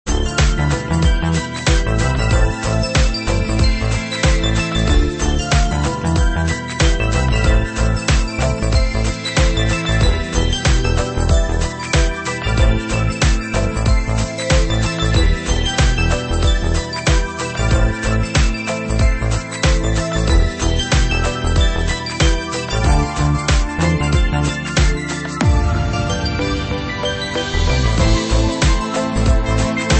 [Lo-Fi preview]